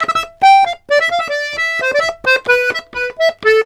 Index of /90_sSampleCDs/USB Soundscan vol.40 - Complete Accordions [AKAI] 1CD/Partition C/04-130POLKA
S130POLKA4-L.wav